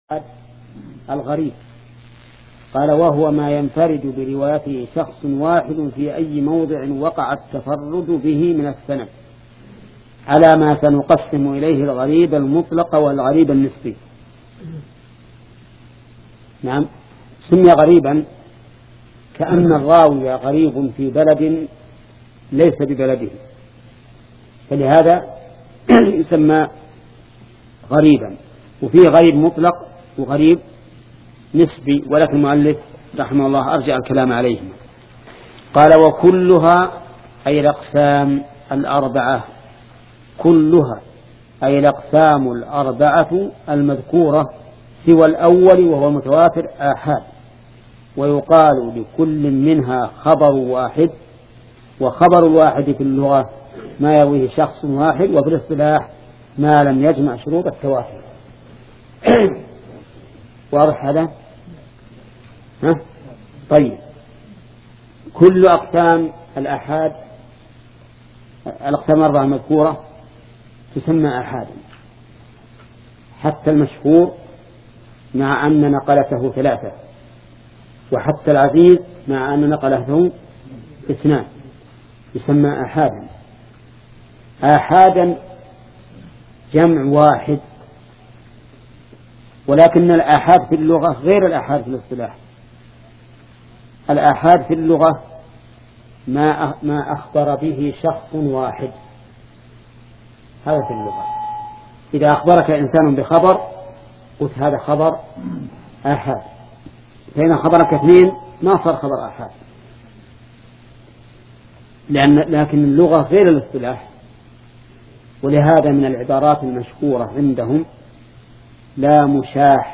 شبكة المعرفة الإسلامية | الدروس | شرح نخبة الفكر (3) |محمد بن صالح العثيمين